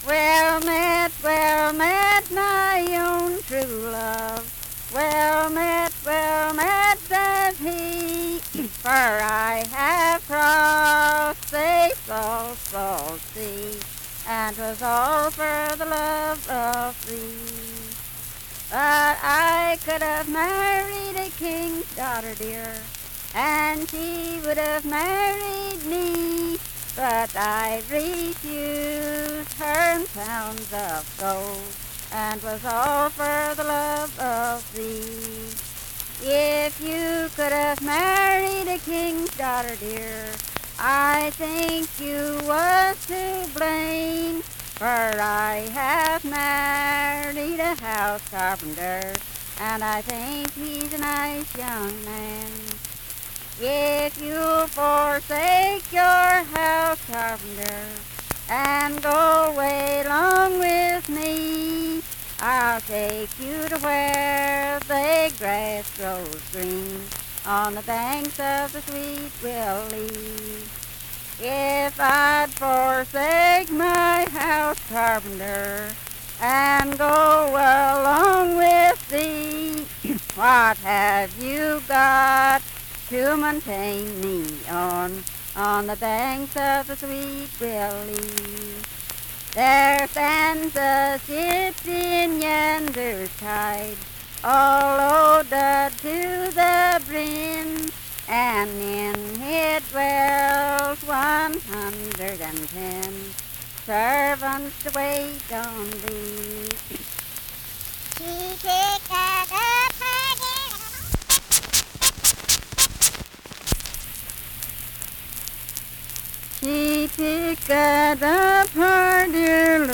Unaccompanied vocal music
Performed in Daybrook, Monongalia County, WV.
Voice (sung)